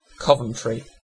Ääntäminen
Synonyymit Cov Ääntäminen UK UK : IPA : /ˈkɒvəntɹi/ IPA : [ˈkʰɒvəntʃɹi] US : IPA : /ˈkɑvəntɹi/ IPA : [ˈkʰɑvəntʃɹi] Haettu sana löytyi näillä lähdekielillä: englanti Käännöksiä ei löytynyt valitulle kohdekielelle.